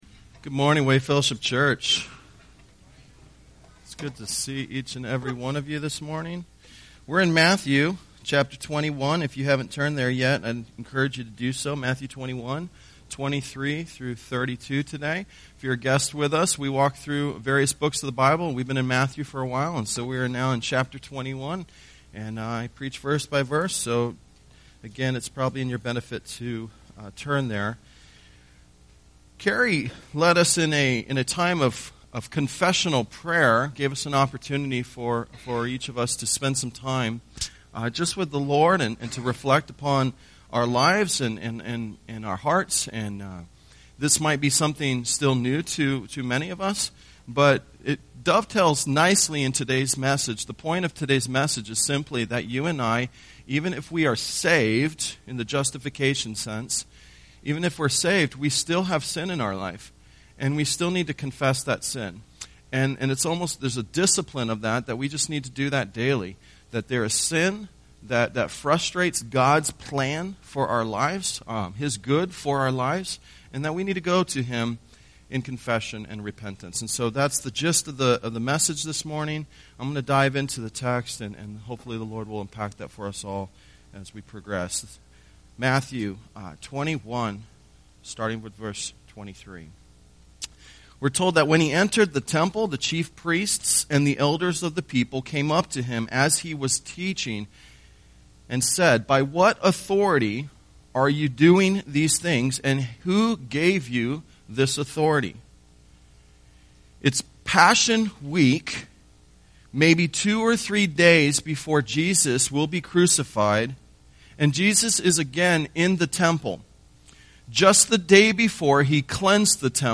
Tagged with Sunday Sermons